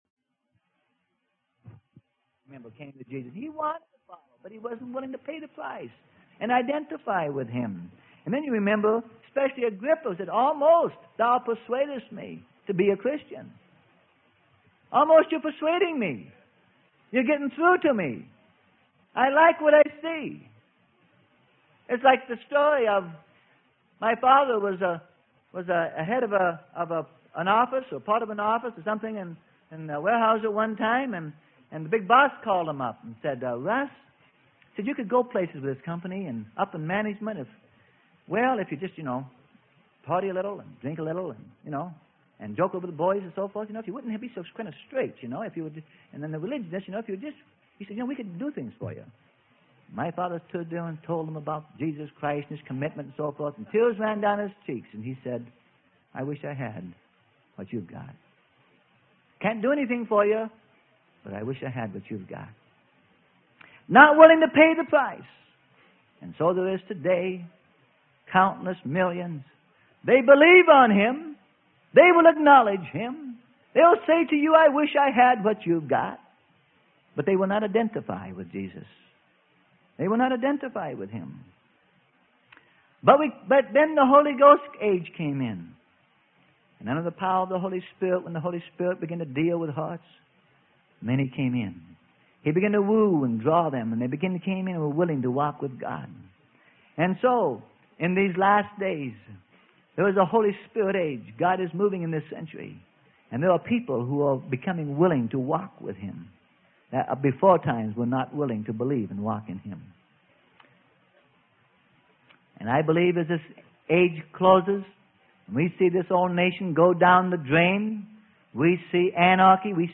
Sermon: Through Death With Him - Part 04 - Freely Given Online Library